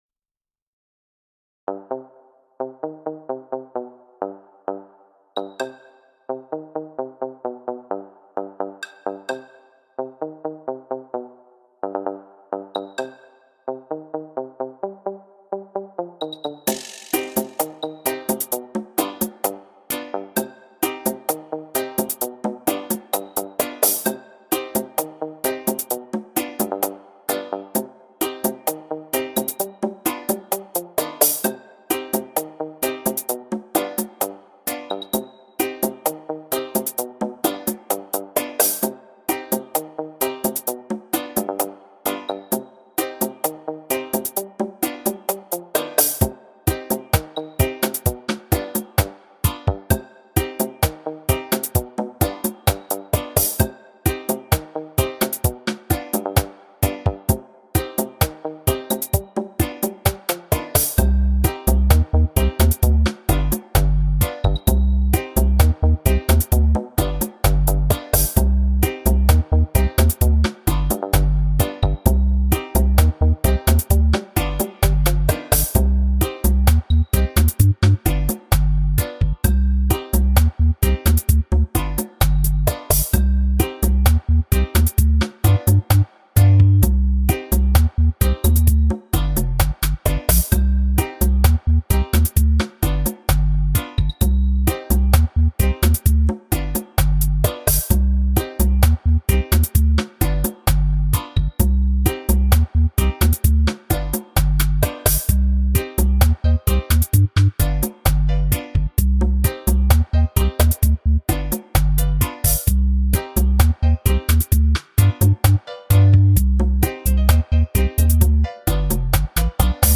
Last riddim !!!